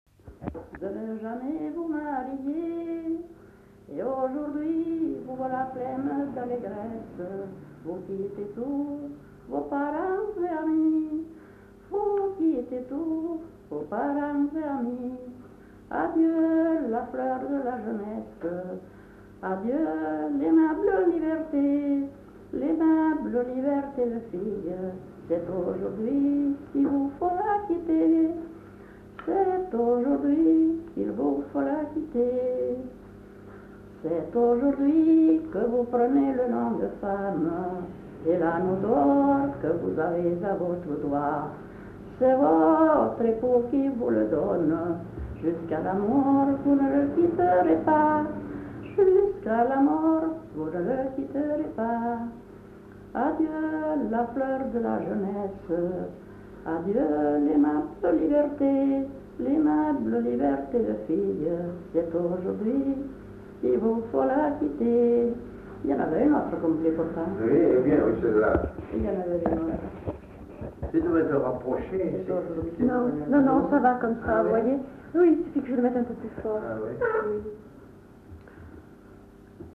[Brocas. Groupe folklorique] (interprète)
Aire culturelle : Marsan
Genre : chant
Effectif : 1
Type de voix : voix de femme
Production du son : chanté
Description de l'item : fragment ; 2 c. ; refr.
Notes consultables : Le début du chant n'est pas enregistré.